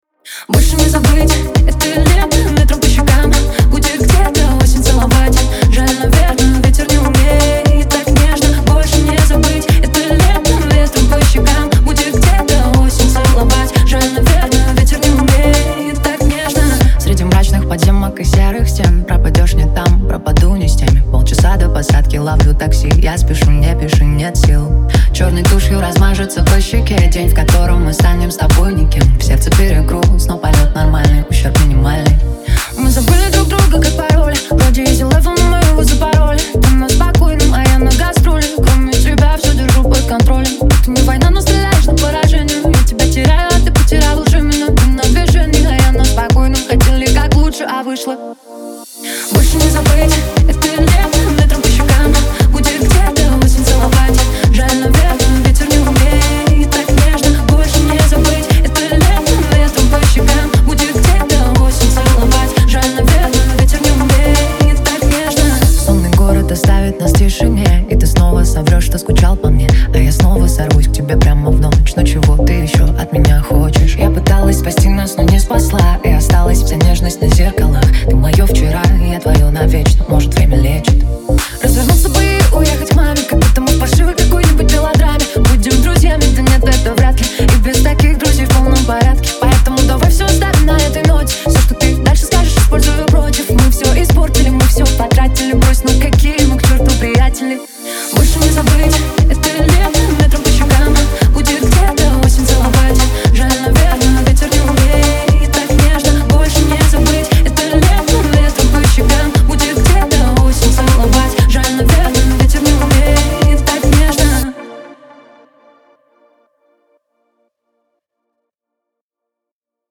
это современный поп-трек